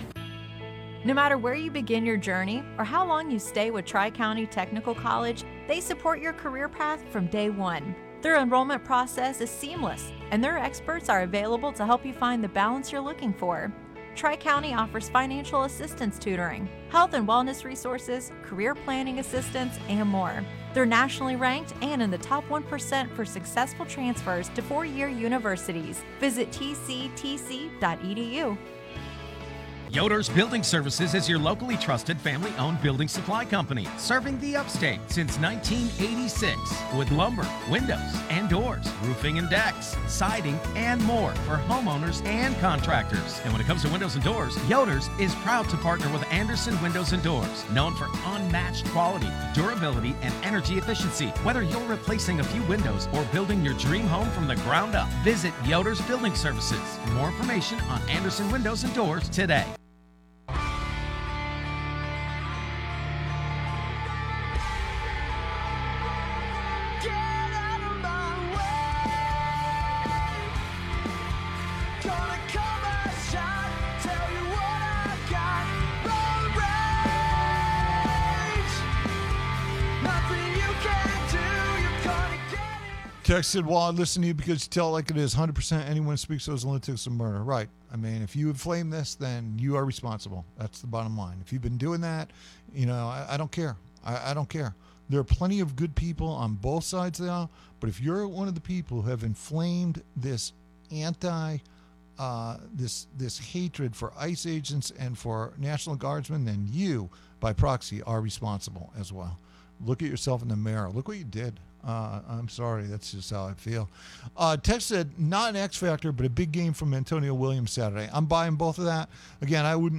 He brings his fiery and passionate personality to the airwaves every day, entertaining listeners with witty comments, in depth analysis and hard-hitting interviews.